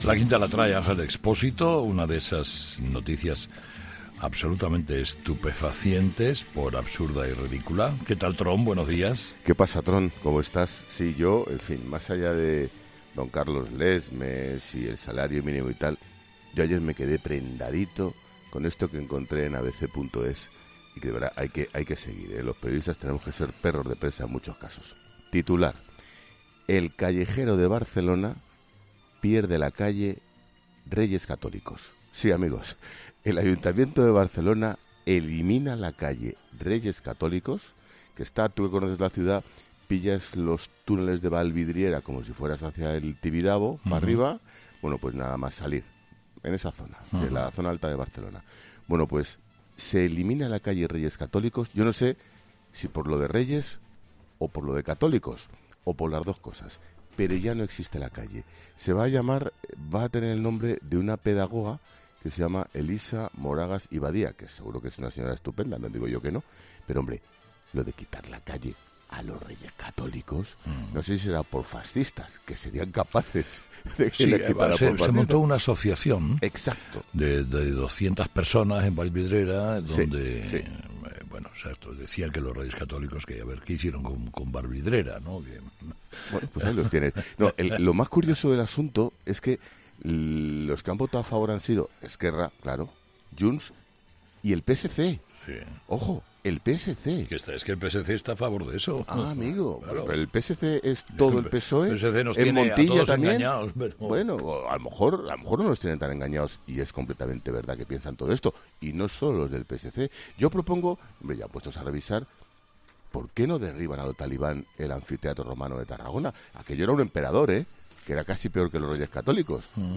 Ángel Expósito hace su paseíllo en 'Herrera en COPE'.